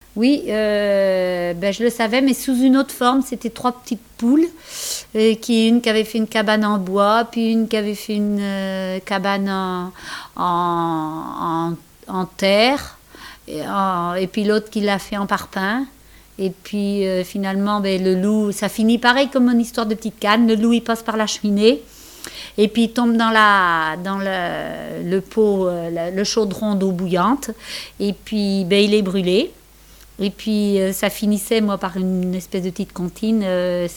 Genre conte